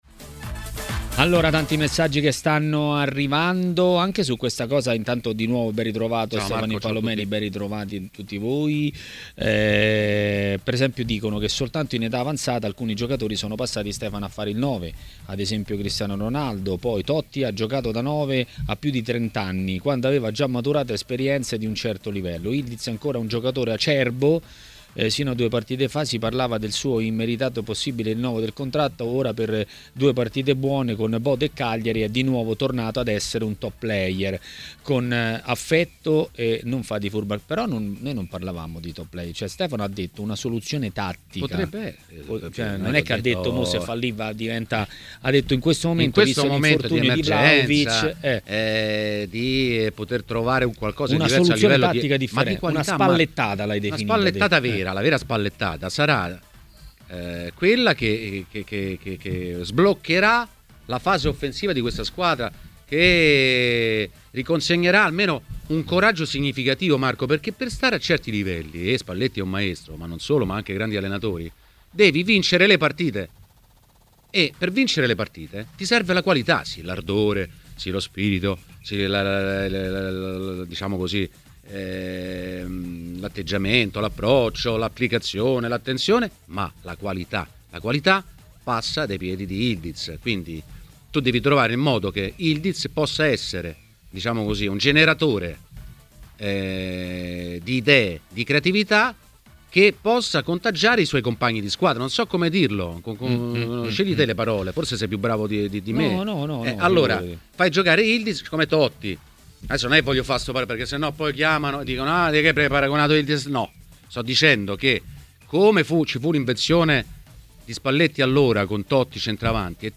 Le Interviste
A Maracanà , nel pomeriggio di TMW Radio , è intervenuto l'ex calciatore Massimo Orlando .